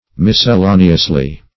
Mis`cel*la"ne*ous*ly, adv.